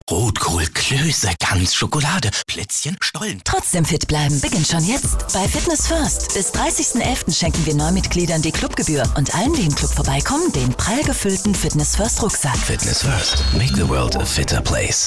Flexibel von jung/dynamisch bis seriös/offig.
Sprechprobe: eLearning (Muttersprache):